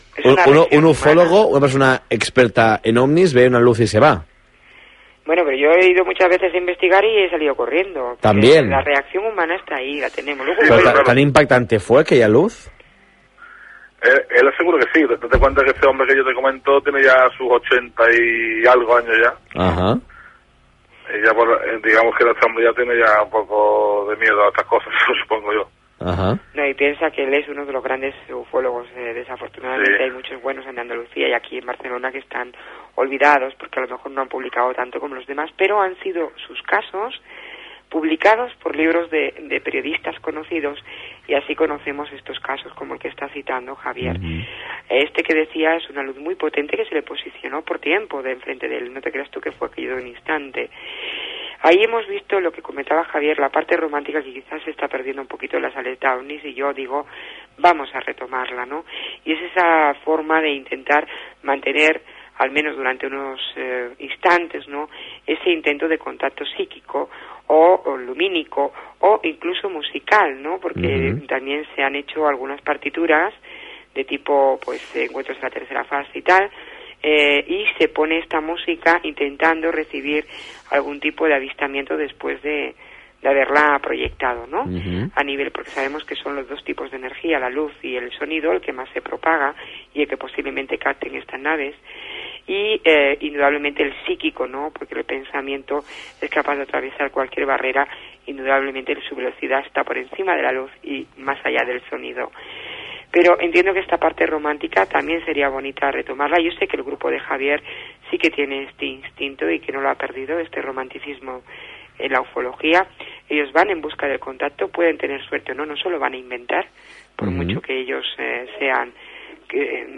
hora, identificació de l'emissora i indicatiu cantat del programa Gènere radiofònic Entreteniment